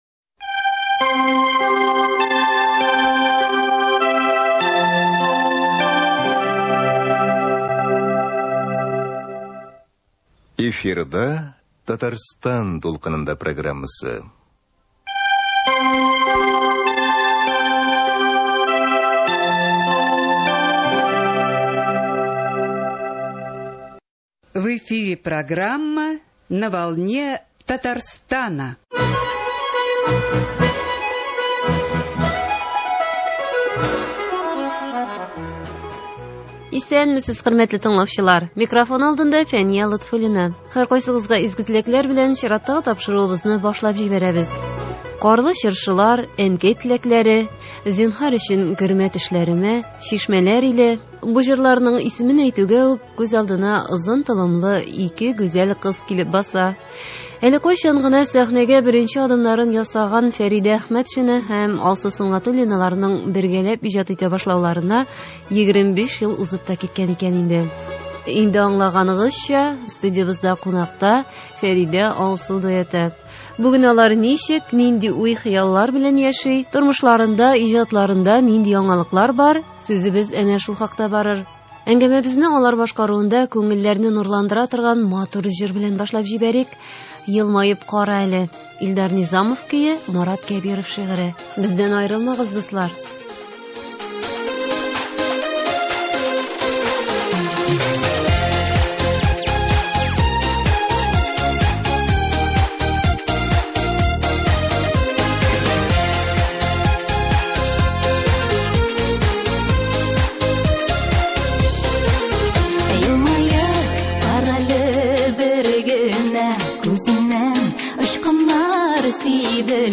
бүген студиябездә кунакта